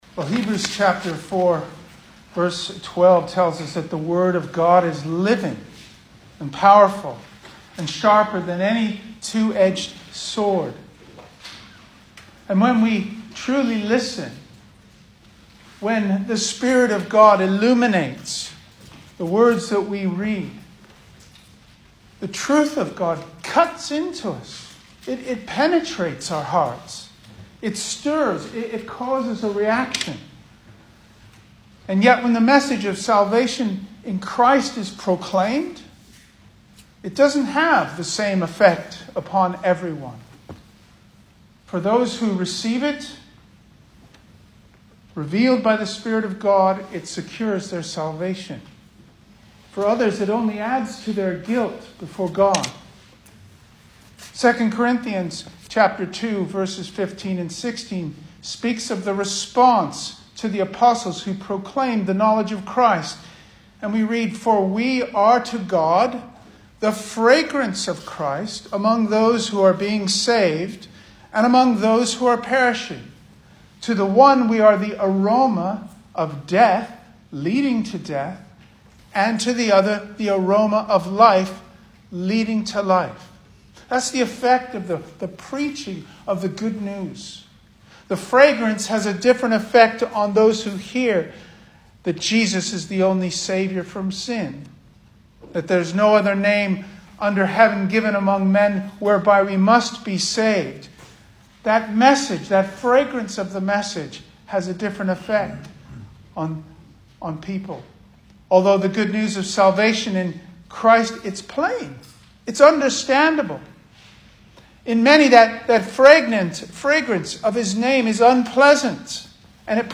2021 Service Type: Sunday Morning Speaker
Single Sermons